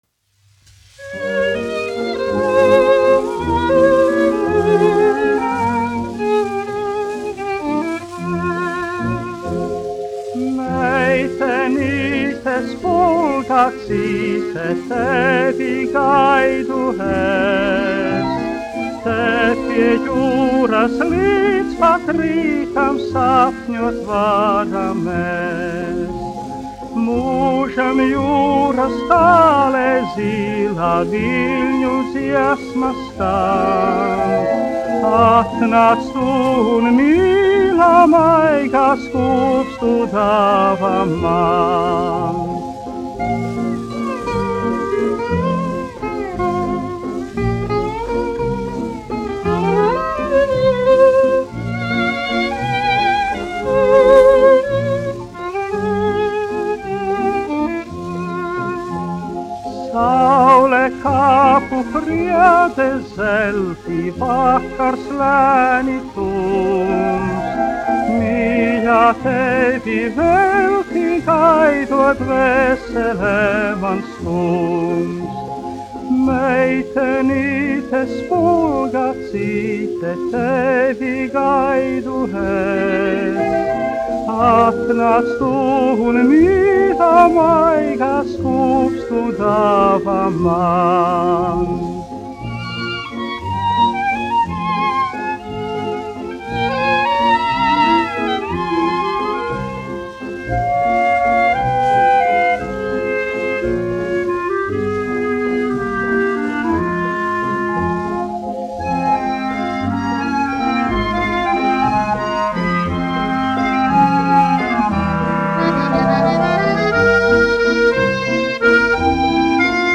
dziedātājs
1 skpl. : analogs, 78 apgr/min, mono ; 25 cm
Populārā mūzika
Skaņuplate